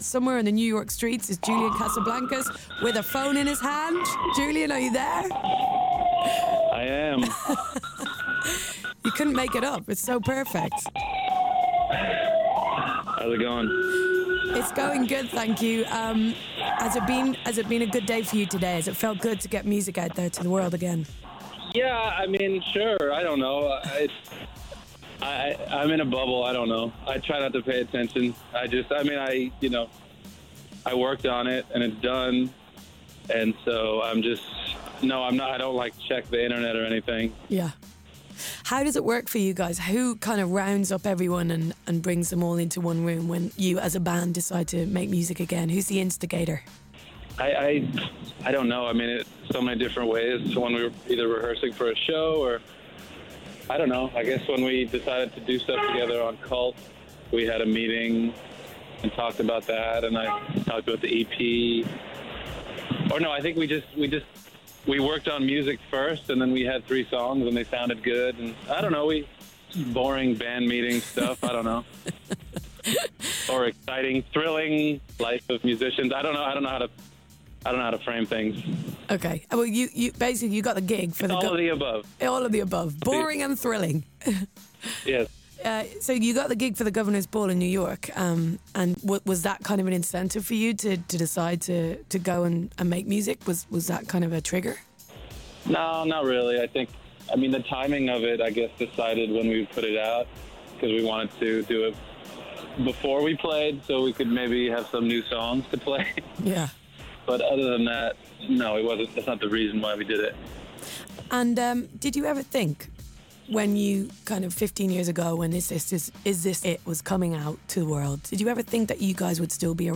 Julian Casablancas interview with BBC Radio 1 (26 May 2016) [Listen below]